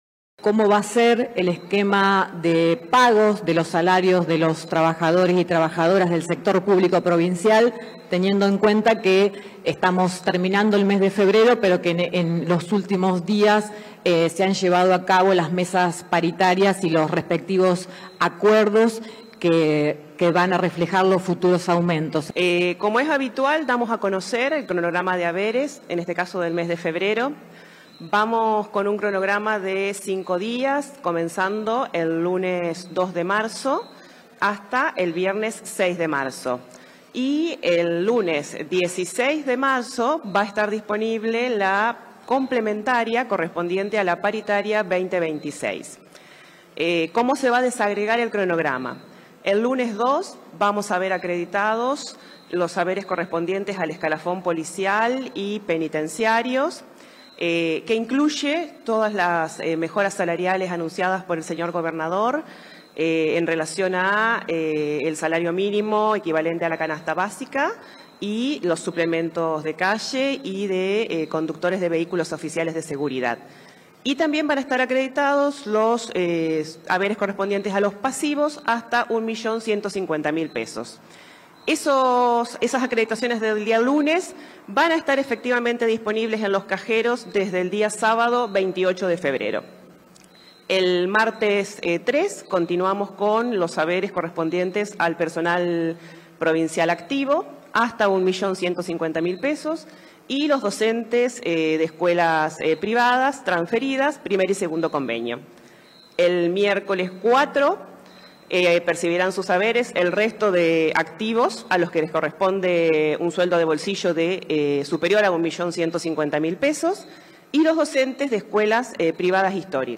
El cronograma comenzará el lunes 2 de marzo y se extenderá hasta el viernes 6 de marzo, según detallaron la secretaria de Función Pública y Recursos Humanos del Ministerio de Economía, Malena Azario; la secretaria de Hacienda, Belén Etchevarría, y la secretaria de Gestión Territorial Educativa, Daiana Gallo Ambrosis, en el marco de una conferencia de prensa en la Sala Auditorio de Casa de Gobierno en la ciudad de Santa Fe.
Azario, Etchevarría y Gallo Ambrosis brindando detalles del cronograma